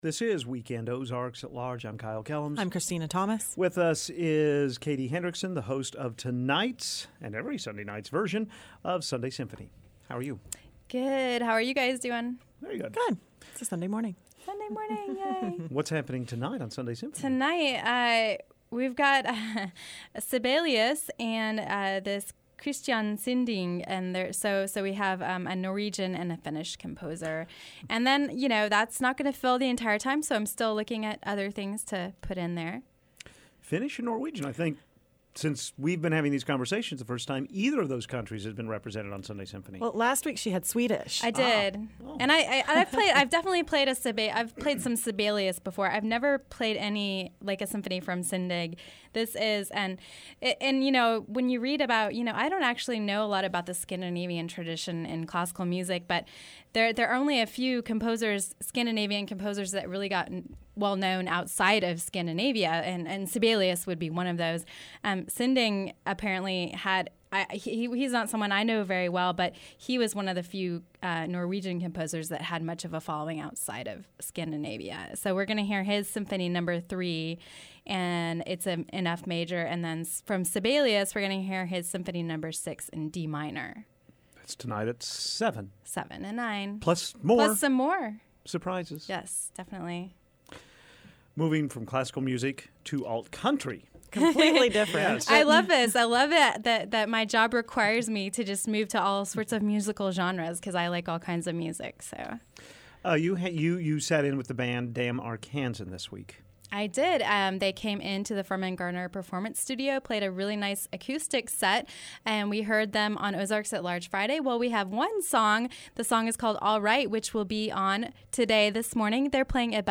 in addition to giving us another song performed by Damn Arkansan in the Firmin-Garner Performance Studio.